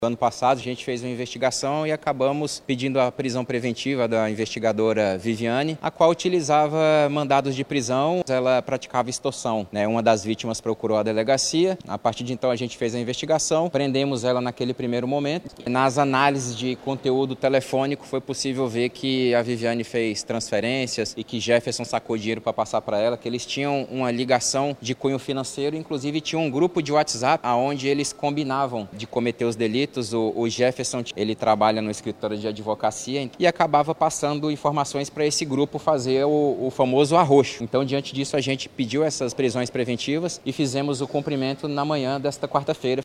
SONORA01_DELEGADO.mp3